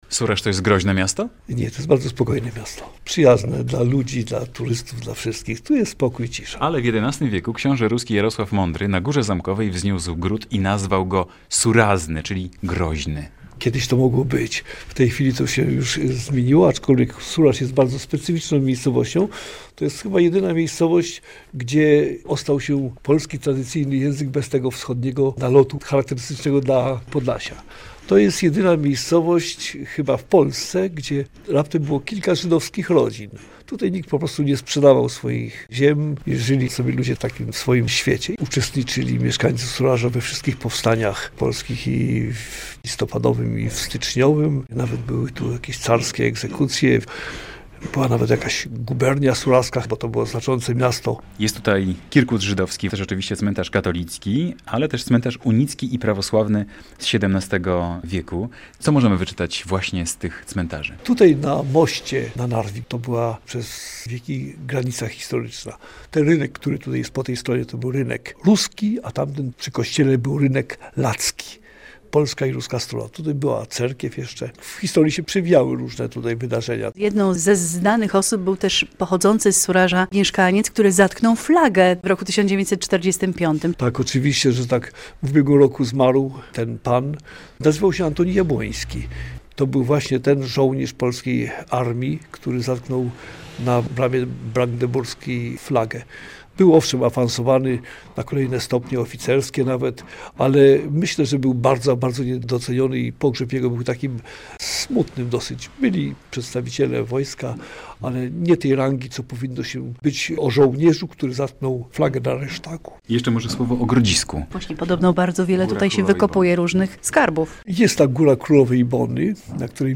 rozmawiają